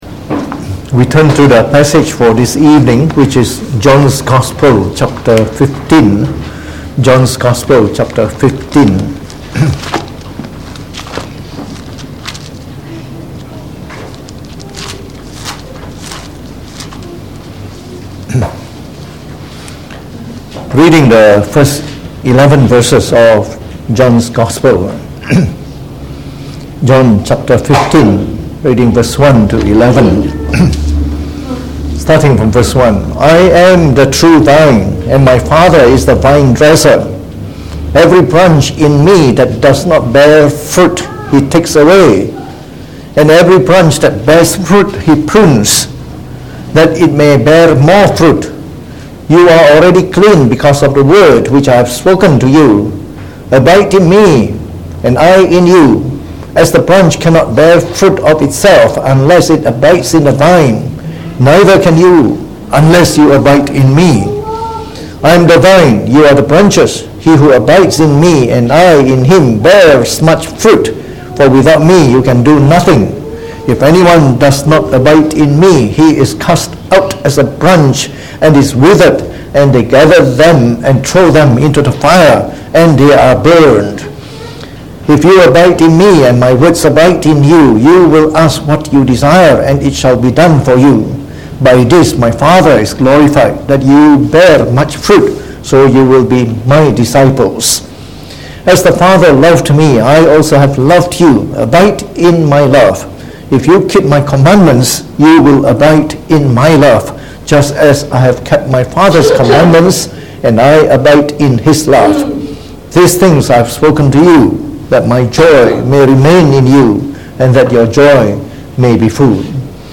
Preached on the 28th April 2019.